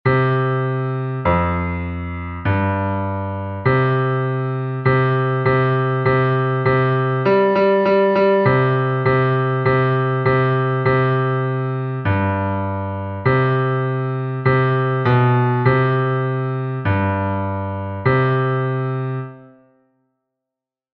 basses-mp3 6 novembre 2022